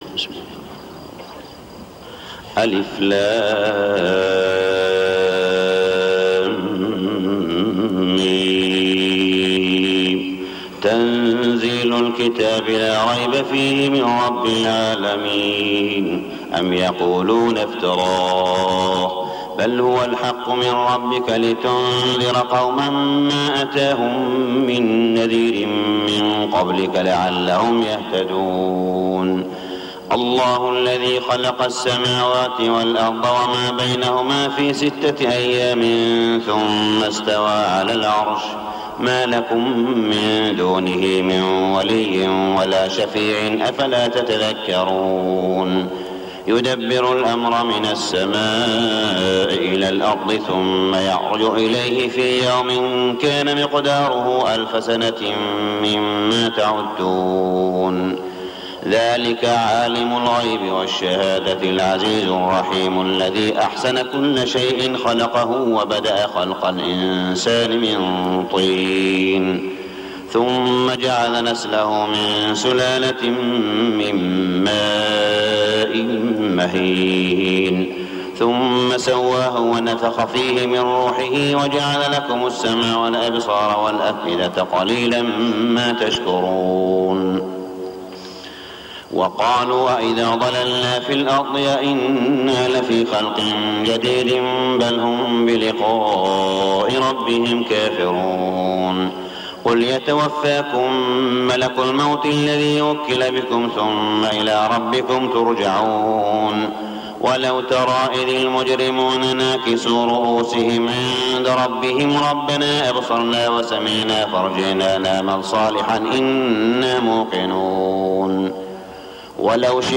سورة السجدة 1421هـ | Surah As-Sajda > السور المكتملة للشيخ صالح بن حميد من الحرم المكي 🕋 > السور المكتملة 🕋 > المزيد - تلاوات الحرمين